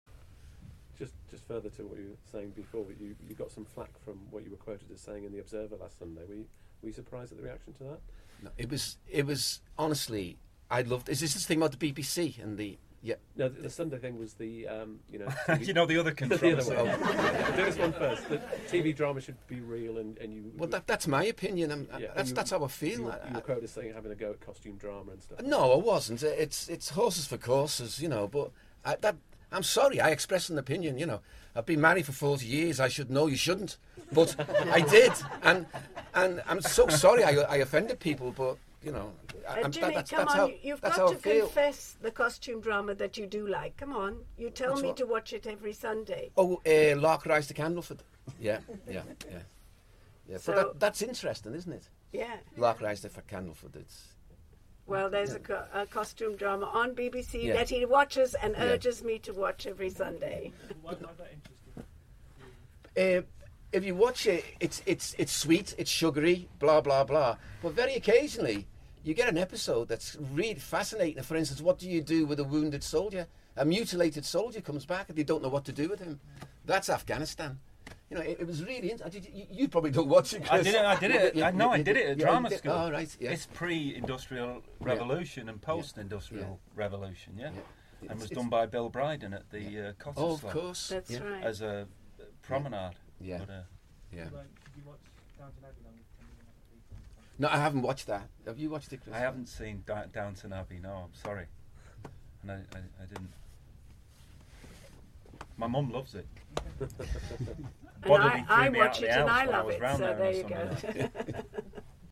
BACK from a screening plus Q&A tonight for new BBC1 drama series Accused.